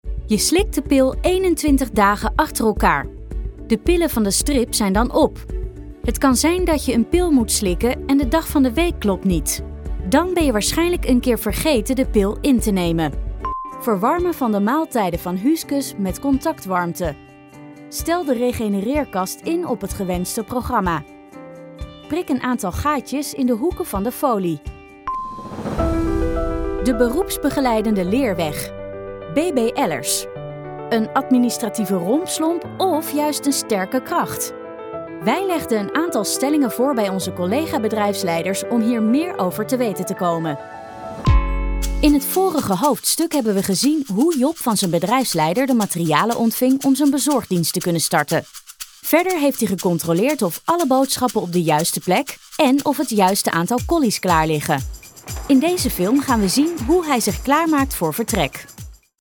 E-learning
My voice sounds young, fresh and enthusiastic, but reliable.
Mic: Sennheiser MKH416